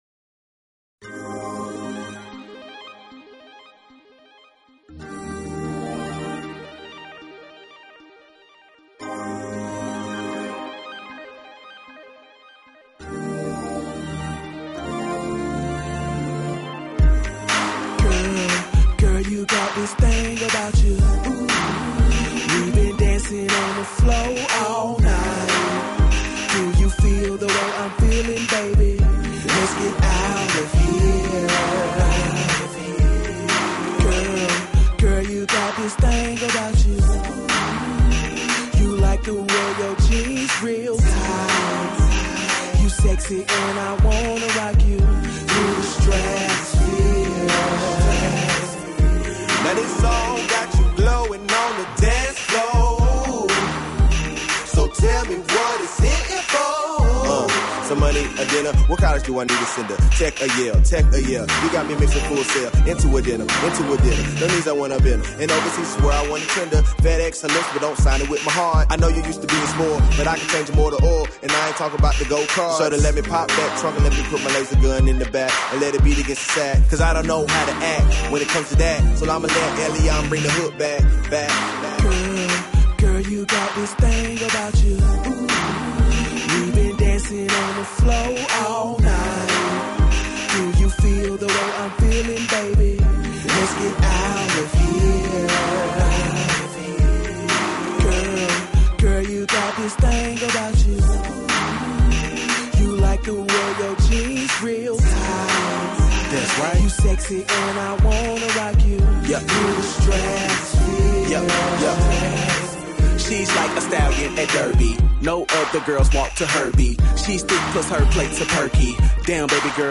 Talk Show Episode, Audio Podcast, Todays_Entrepreneurs and Courtesy of BBS Radio on , show guests , about , categorized as
During each broadcast, there will be in-depth guest interviews discussing the problems and advantages business owners face. Topics will include sales and marketing, branding, interviewing, and much more.
In addition to daily guests, TE spins the hottest indie musical artists and laughs along to the funniest comedians every day.